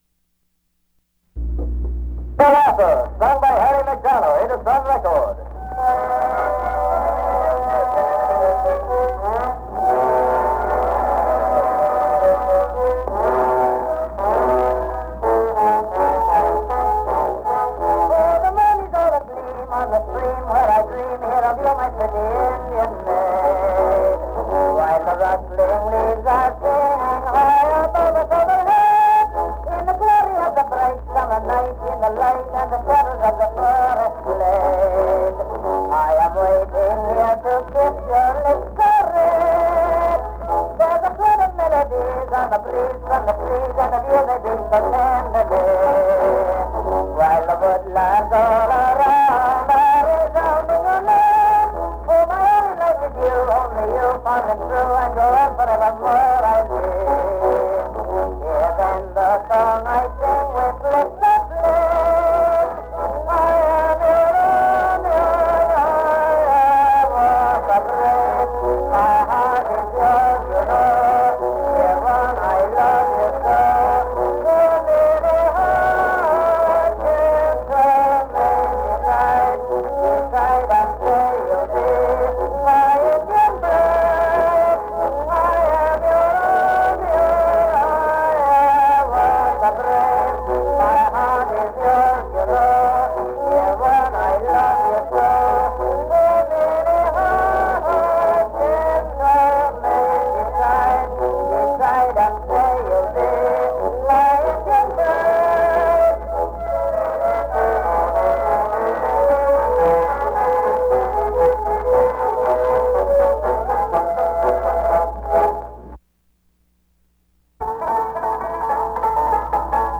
on an old Columbia cylinder
Popular music